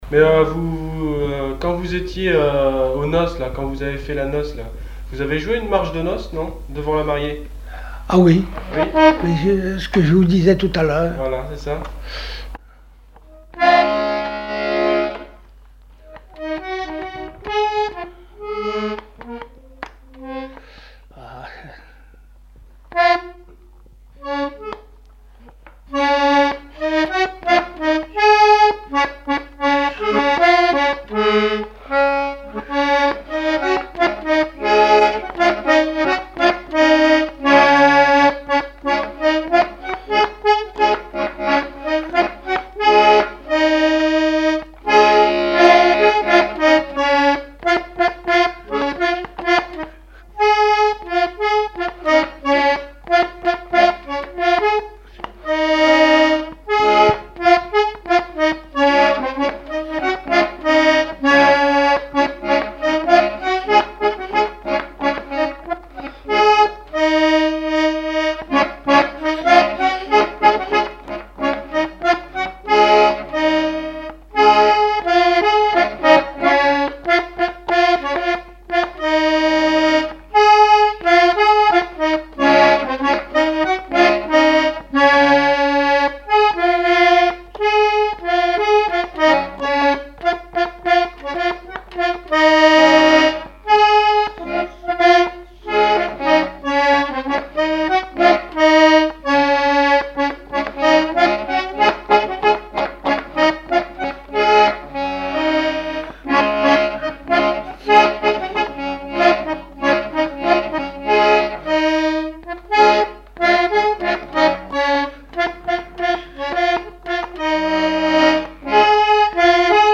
Marche de noce
Aiguillon-sur-Vie (L') ( Plus d'informations sur Wikipedia ) Vendée
Répertoire instrumental à l'accordéon diatonique
Pièce musicale inédite